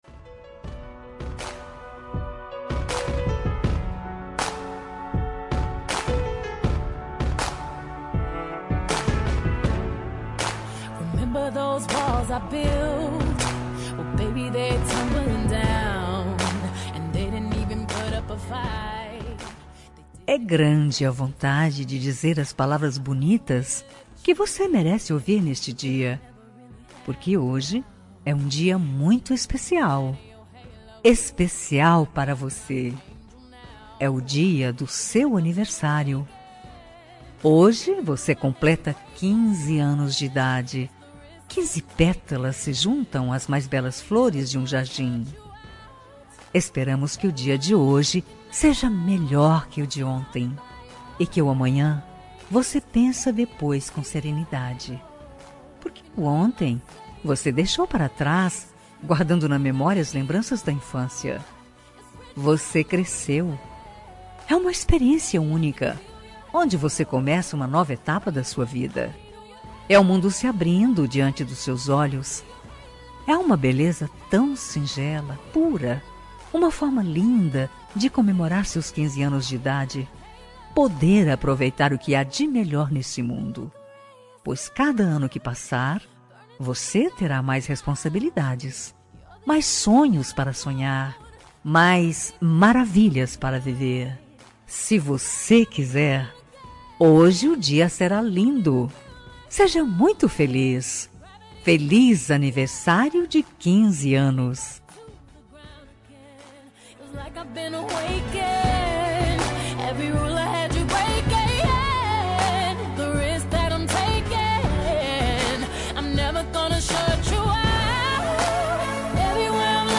Aniversário de 15 anos – Voz Feminina – Cód: 33369